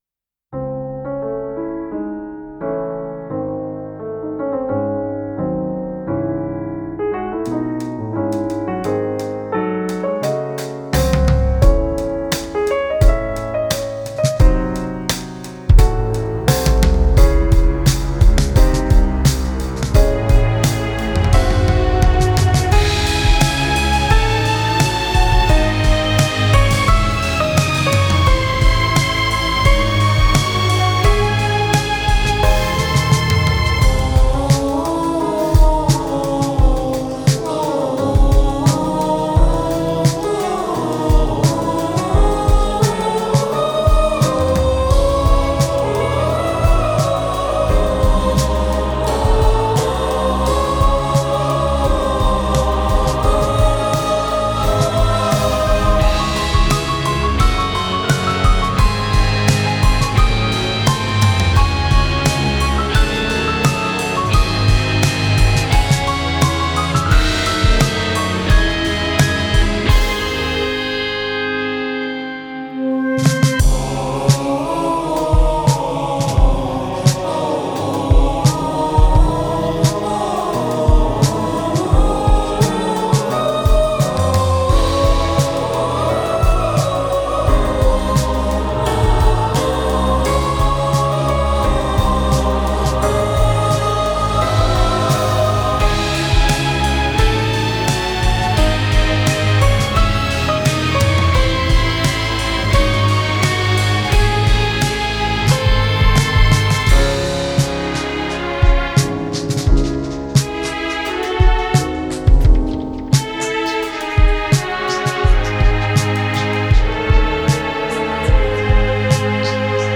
Epic, Suspense - Classical / Cinematic / Hip-Hop